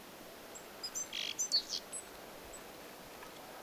hömötiaislintu, 2
homotiaislintu2.mp3